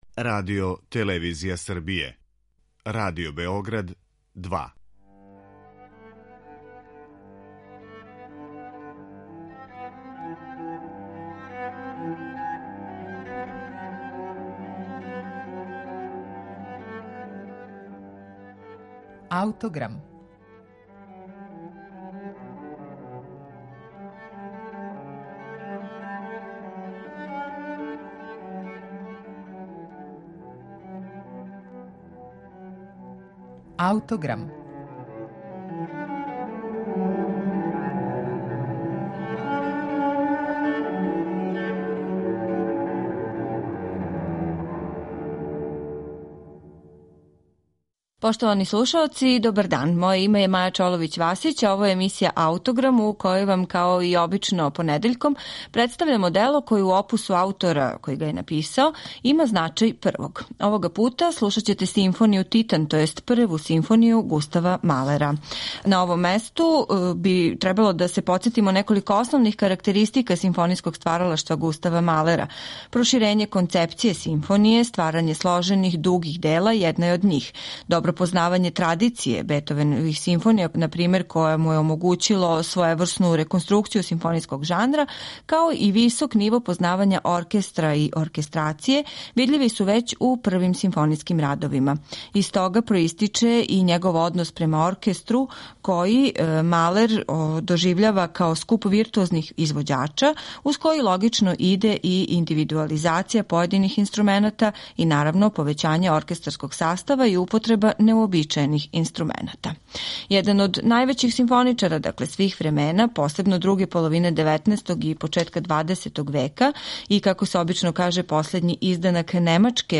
у Де-дуру